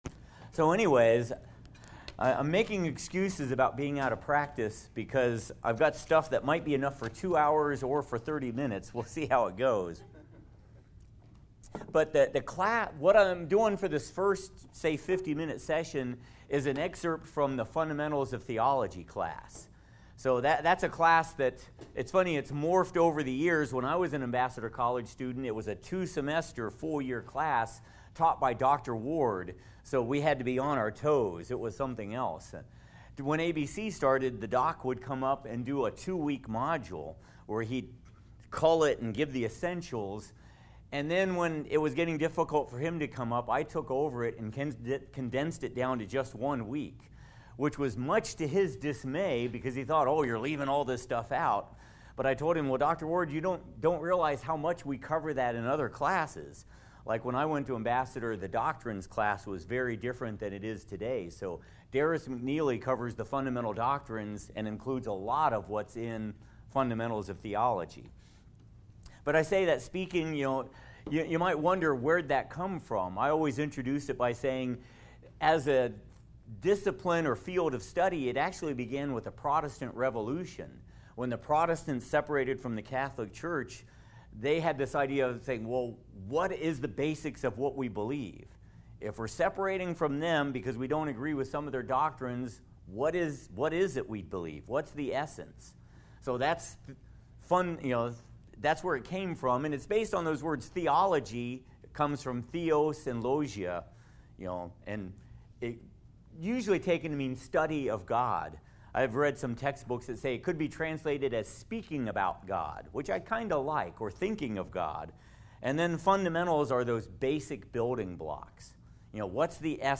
Part one of a seminar concerning of the principles of hermeneutics and rules of exegesis.